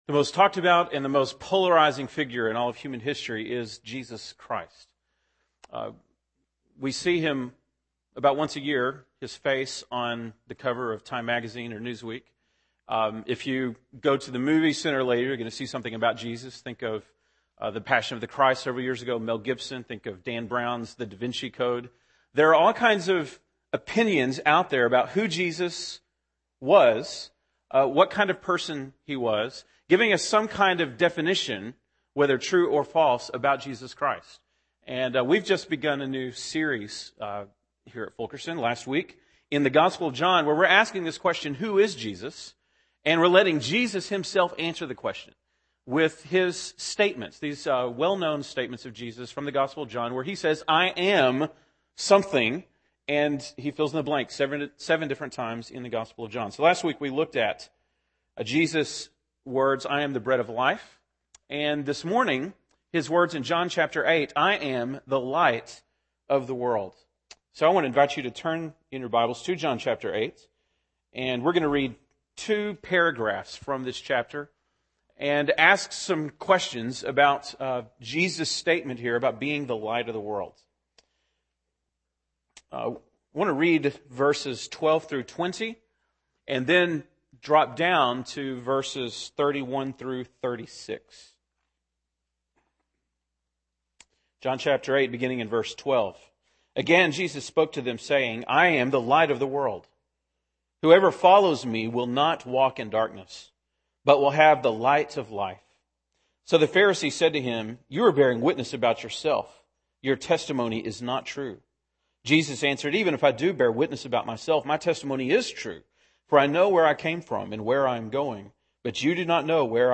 November 14, 2010 (Sunday Morning)